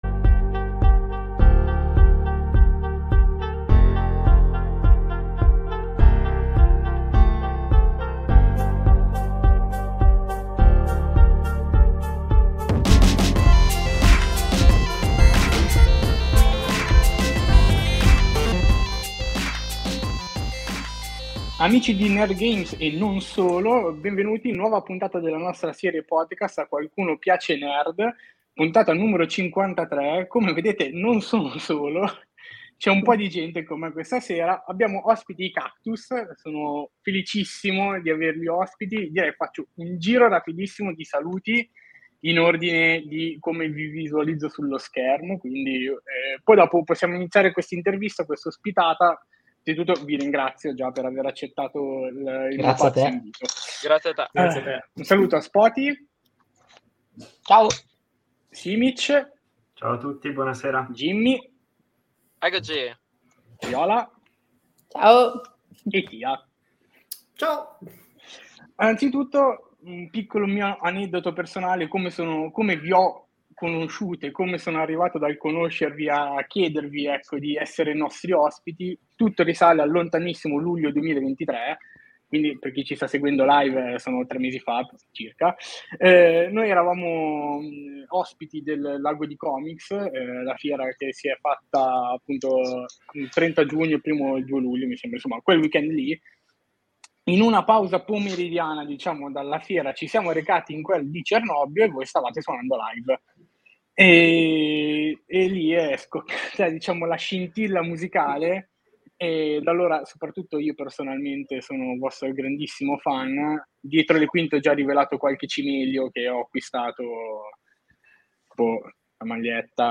Il nuovo episodio della serie podcast di NerdGames, A qualcuno piace Nerd. In questa puntata intervistiamo I Cactus.